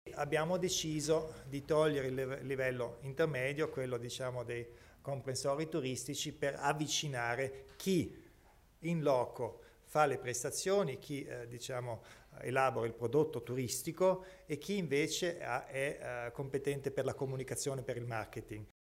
Il Presidente Kompatscher illustra le novità in tema di turismo